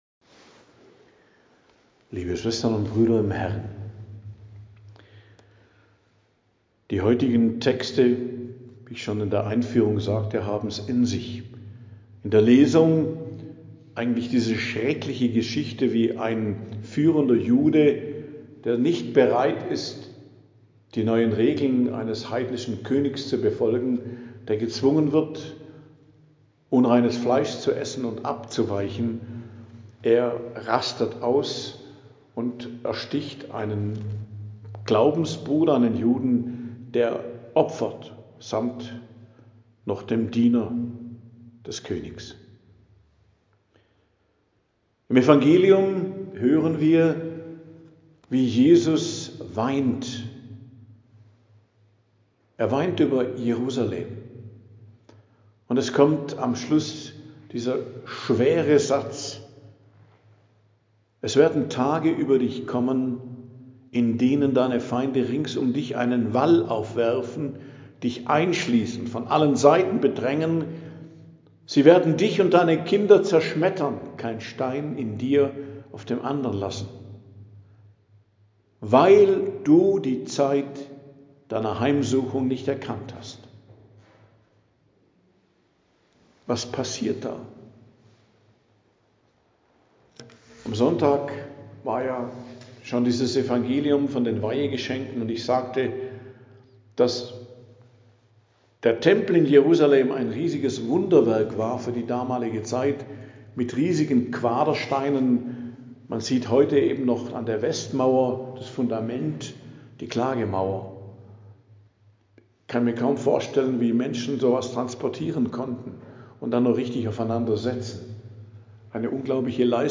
Predigt am Donnerstag der 33. Woche i.J., 20.11.2025 ~ Geistliches Zentrum Kloster Heiligkreuztal Podcast